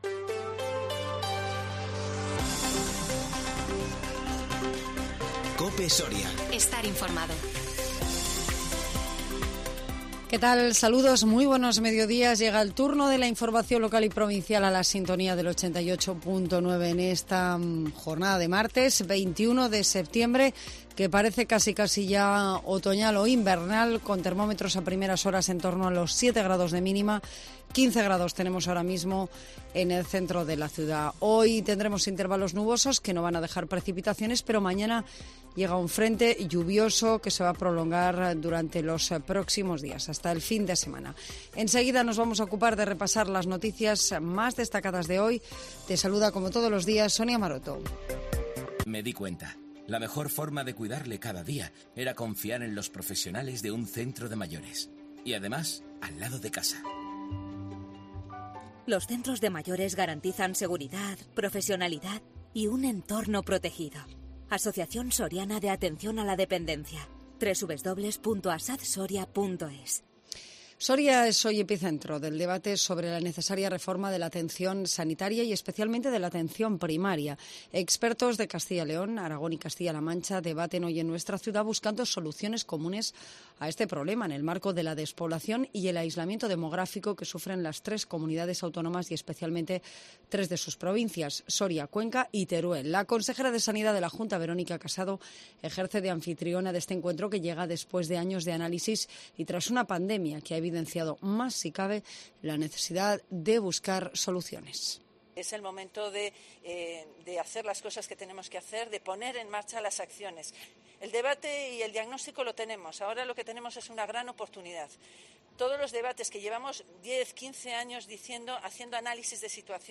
INFORMATIVO MEDIODÍA 21 SEPTIEMBRE 2021